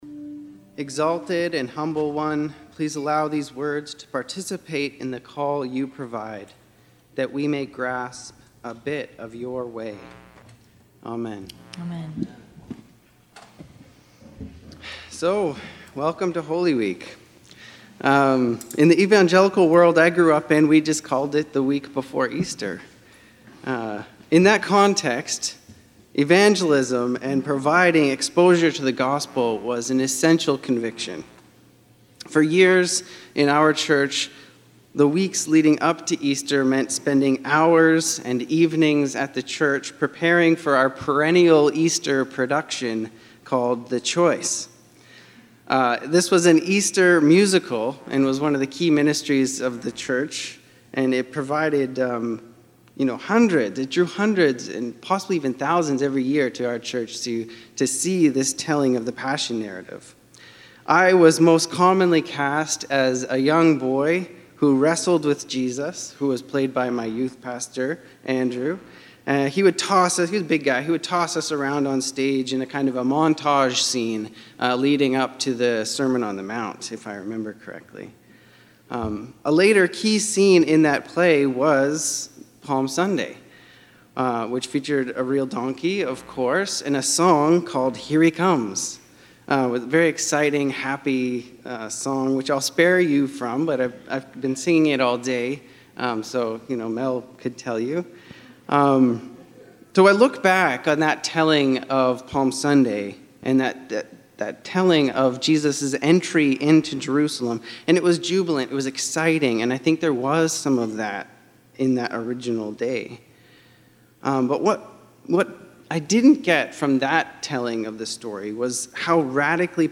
Sermons | the abbeychurch